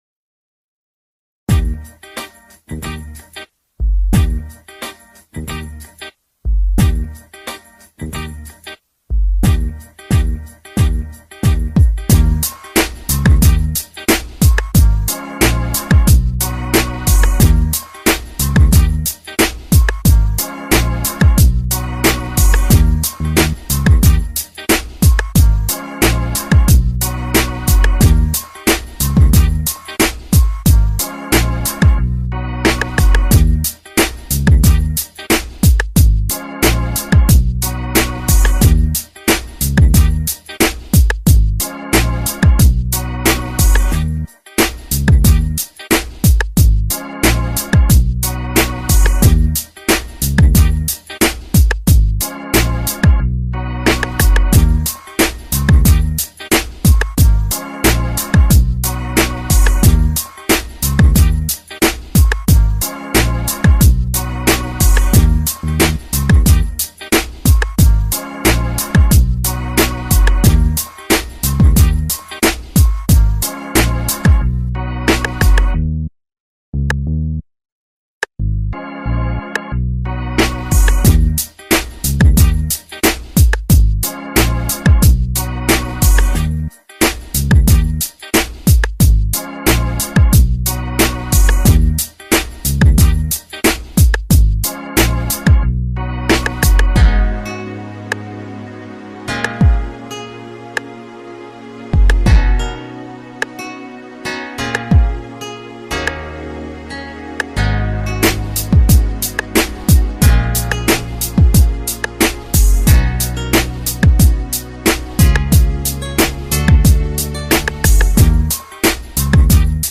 Rhythm and Blues Instrumentals